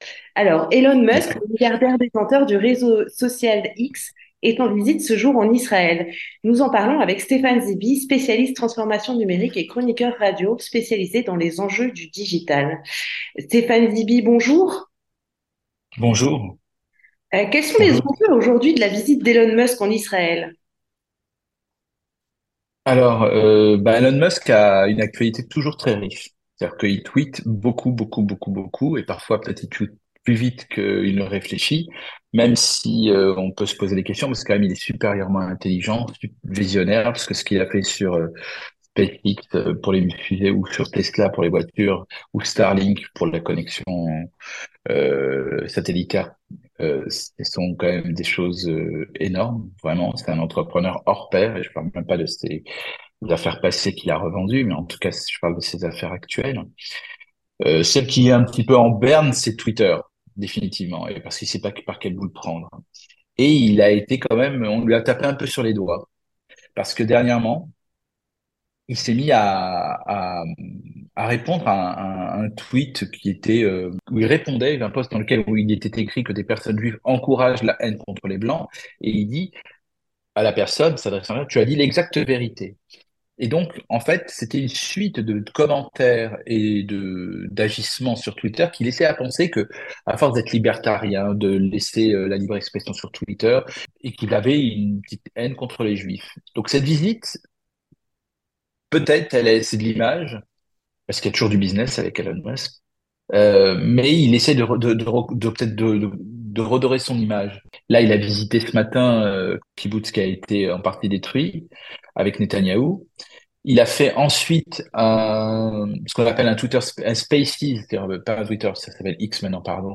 L'entretien du 18H - Elon Musk était en Israël.